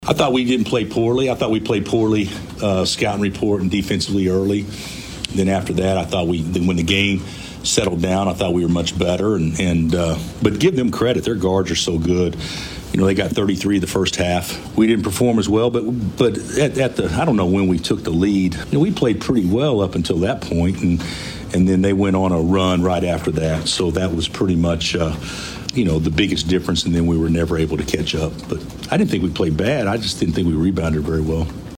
Kansas Coach Bill Self said they didn’t play badly, Baylor was better.
1-24-bill-self-on-loss-to-baylor.mp3